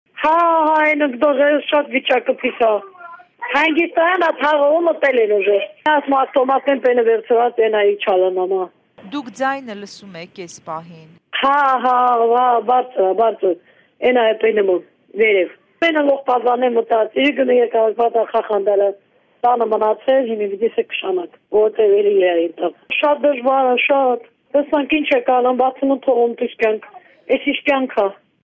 «Վիճակը շատ փիս է, քաղաքում հիմա կրակոցների ձայներ կան»․ Ստեփանակերտի բնակիչ